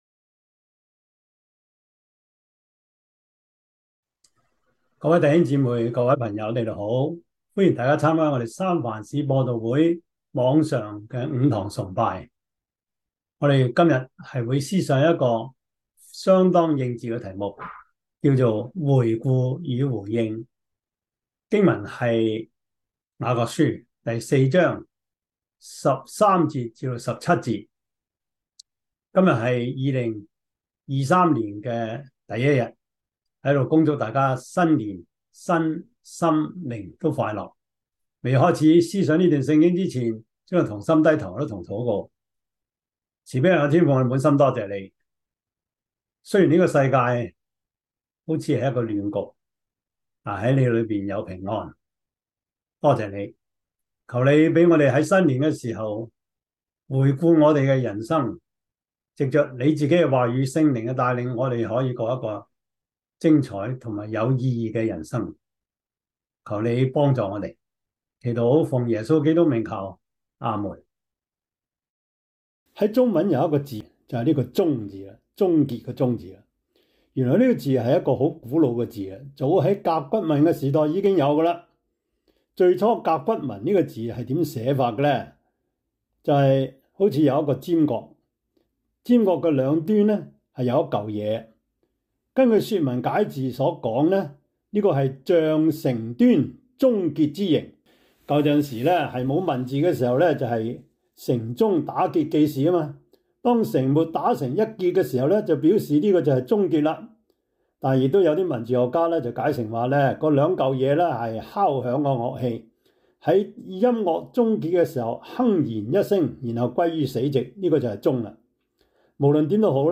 雅各書 4:13-17 Service Type: 主日崇拜 雅 各 書 4:13-17 Chinese Union Version